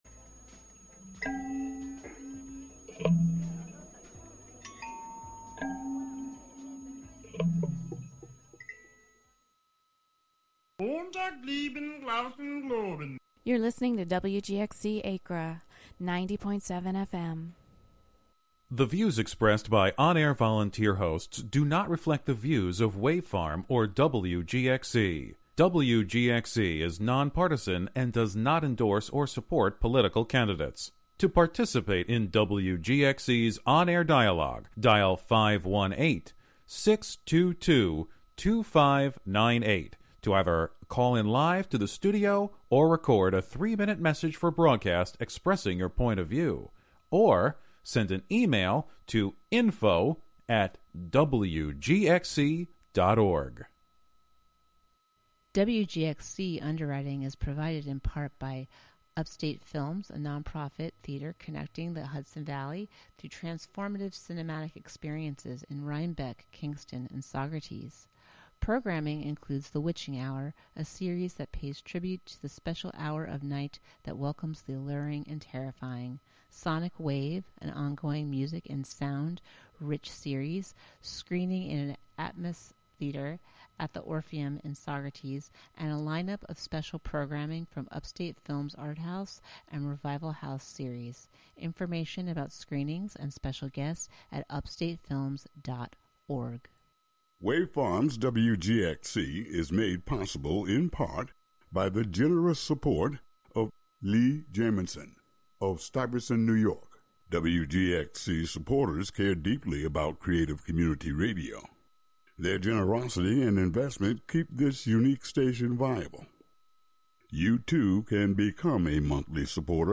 a patchwork of song, sound, and story that sifts through changing moods and places. expect the sound of crickets, manifestos, current song obsessions, overheard poems, dollar bin tapes, and the like, with a lingering emphasis on underground musicians that defy genre and expectation.